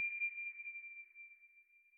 sonarTailWaterFar2.ogg